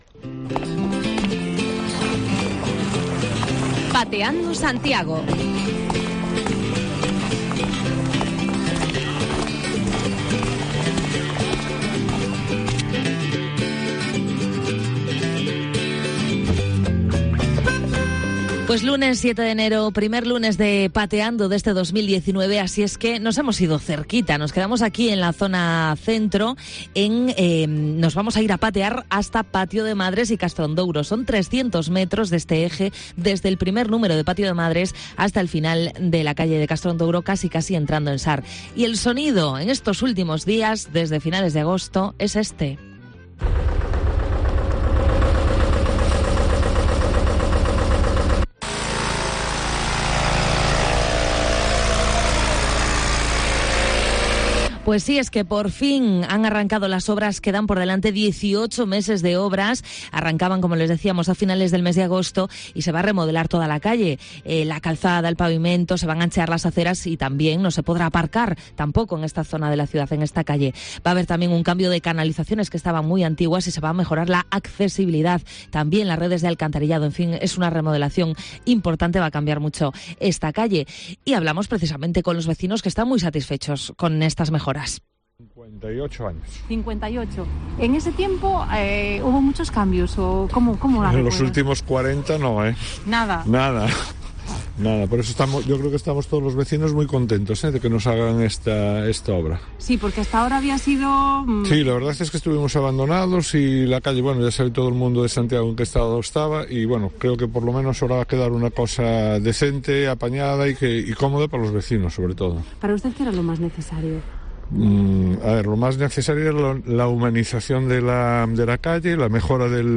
A finales de verano comenzaron las obras que van a cambiar la cara, pero sobre todo, mejorar el tránsito tanto peatonal como de vehículos. Los vecinos con los que hemos charlado se muestran esperanzados en que despues de años de " parches ", por fin se acometan los trabajos que necesita la calle: sustitución de la chapacuña y aceras más anchas que den prioridad a las personas sobre los coches... incluso encontramos partidarios de la peatonalización de esta vía .